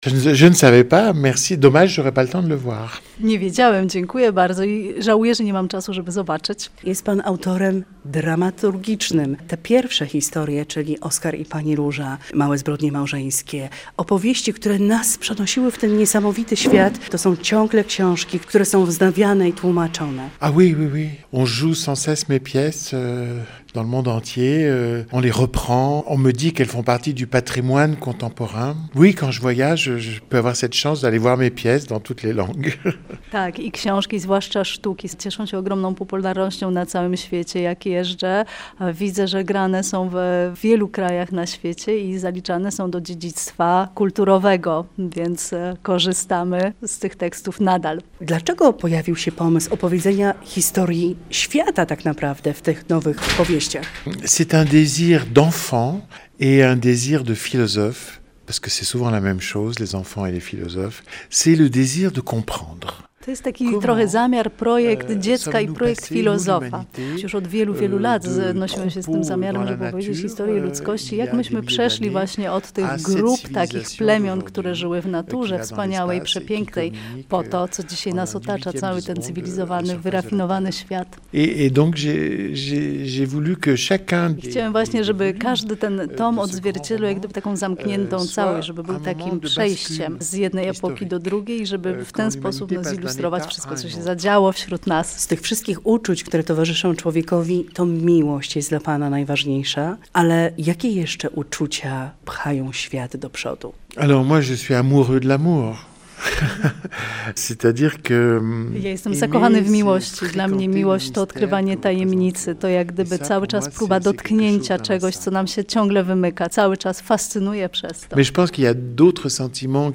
rozmawia
rozmowę tłumaczy